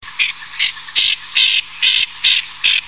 Fuligule nyroca, aythya nyroca
nyroca.mp3